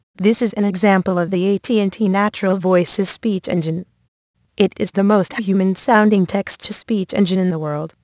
Voice Demo
AT&T Natural Voices ™ Lauren 16k (U.S. English)